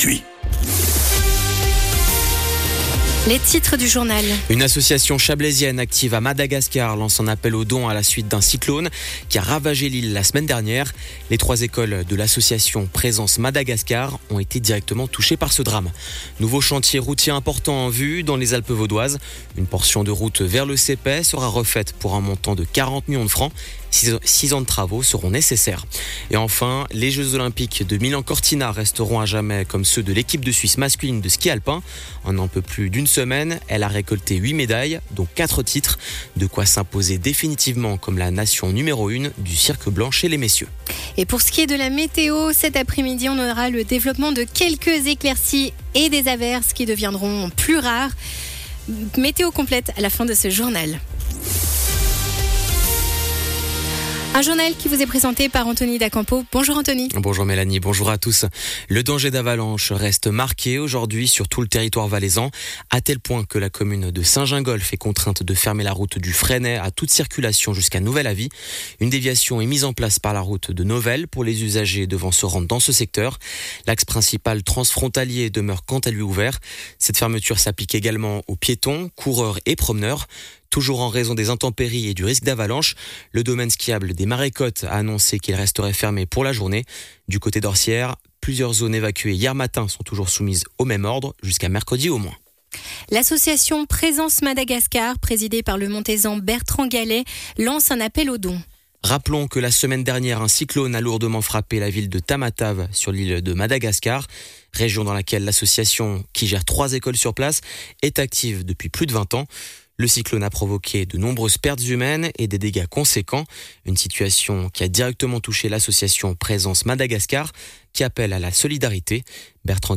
Le journal de midi du 17.02.2026